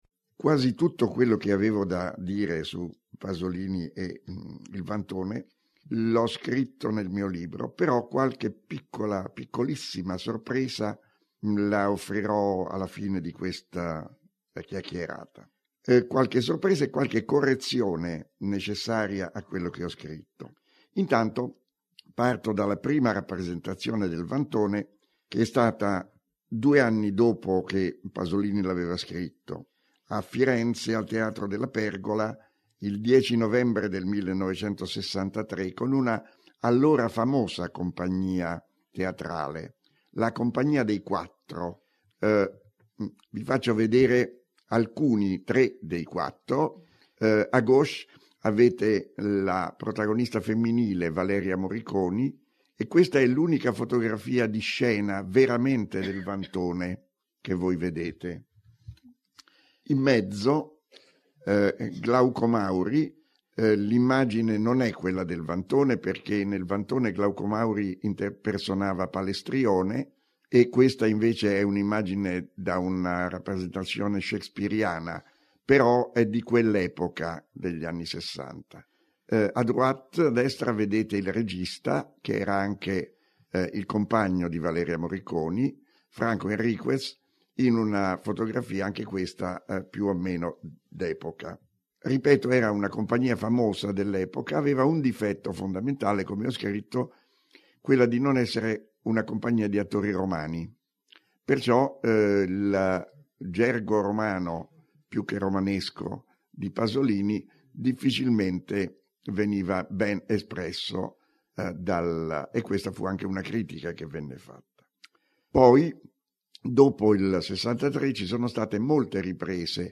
Cette communication a été donnée dans le cadre de la journée d'étude Modernité de l’Antique : Il vantone, une variation pasolinienne conjointement organisée par le LASLAR (EA 4256) et le Centre Michel de Boüard-CRAHAM (UMR 6273). Pier Paolo Pasolini a livré à son époque une œuvre choquante, qui n’a pas manqué de perturber ses lecteurs, spectateurs, œuvre dont l’onde de résonance reste à interroger.